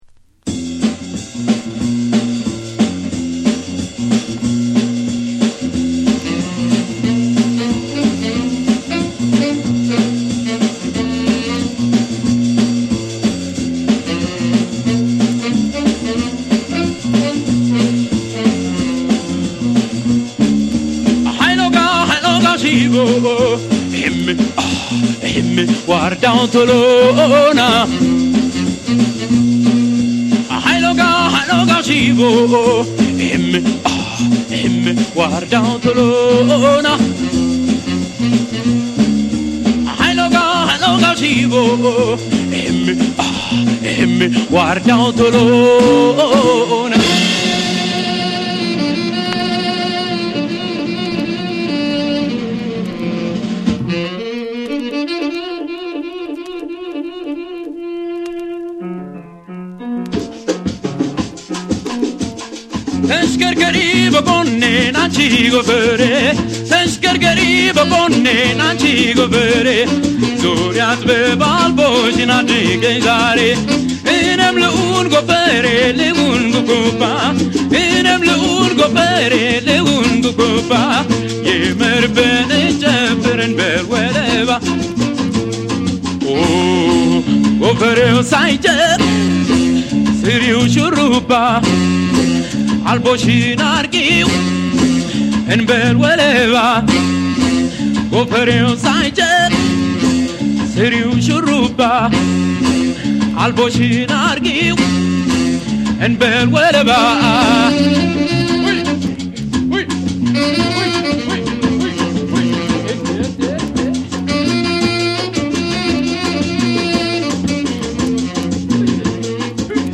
ジャズ、ファンク、ロック、民謡が独自に融合した“エチオ・グルーヴ”の真髄を、名曲・珍曲・発掘音源で網羅！